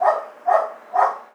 dog_bark_small_06.wav